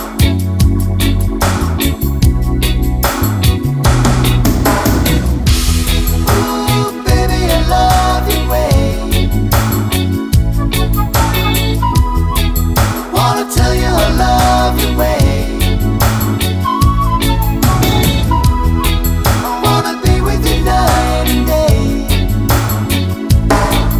no sax solo Reggae 4:20 Buy £1.50